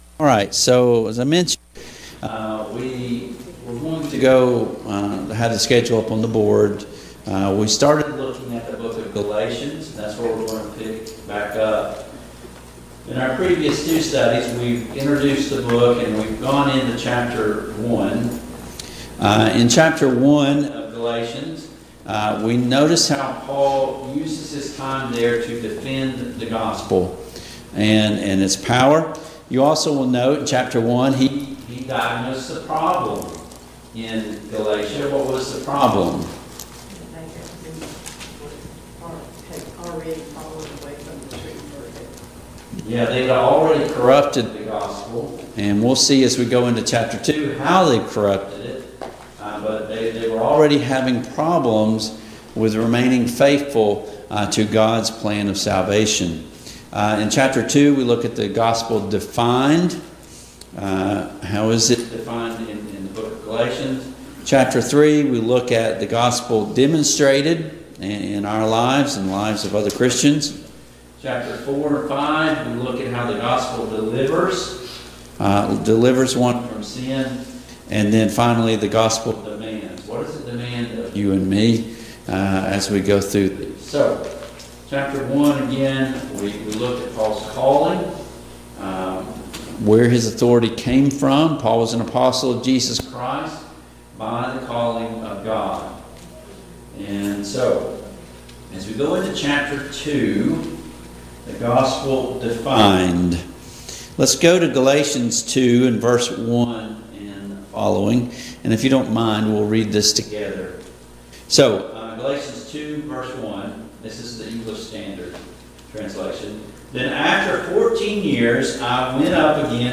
Passage: Galatians 2:1-10, Acts 15:1-6 Service Type: Mid-Week Bible Study